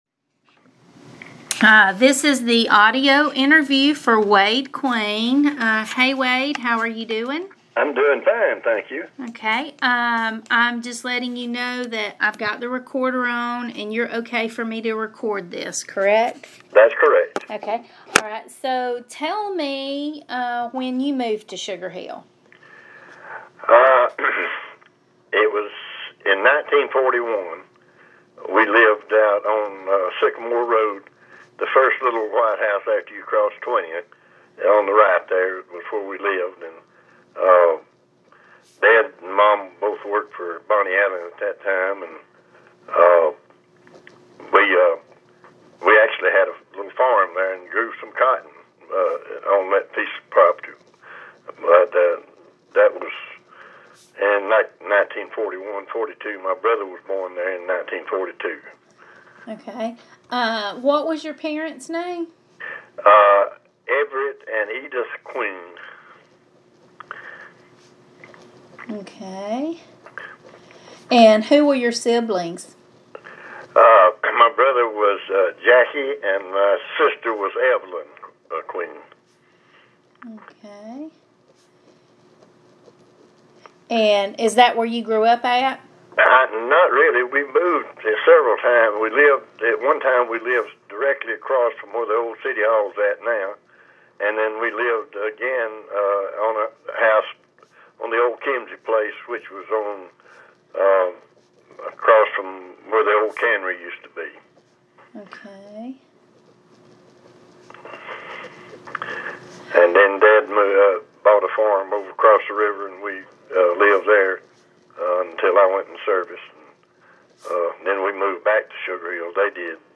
Audio Interview Subject Oral histories Sugar Hill
via telephone